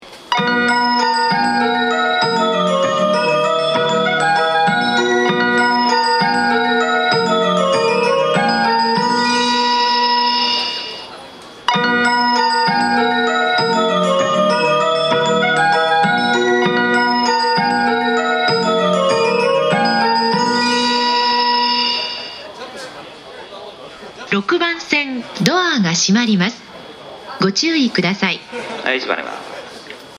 発車メロディー
2コーラスです!特急でも鳴りにくいです。